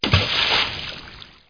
1 channel
splish3.mp3